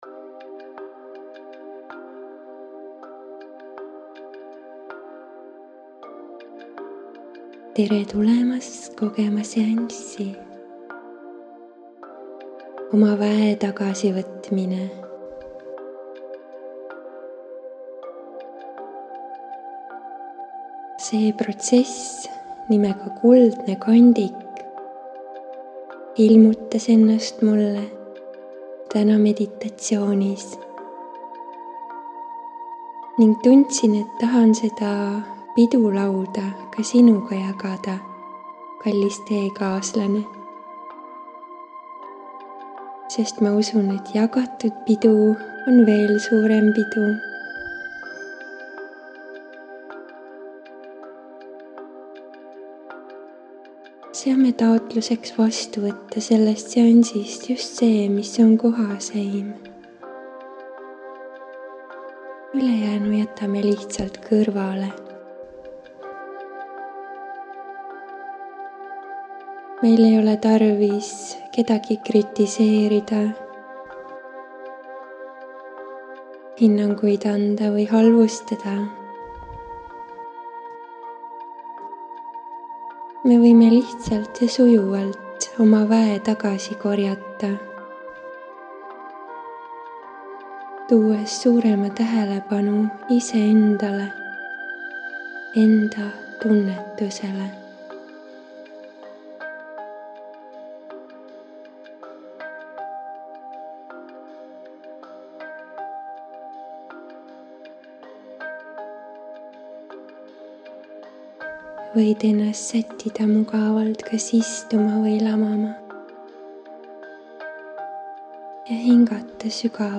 SIIT LEHELT SAAD KUULATA: MEDITATSIOON - MAAGILINE SISERÄNNAK ENDA VÄE TAGASIVÕTMINE salvestatud aastal 2020 Kuldne kandik on energeetiline korrastustöö-protsess, mille sain juhatuseks suurelt terviklikkuse vaimult endale meditatsioonis.
Kolmas osa on pühendatud iseenda väe tagasivõtmisele, seega kolmekordne puhastus ja väestamine. Voog on ka seekord mänguline ja rahustav, nii nagu ikka.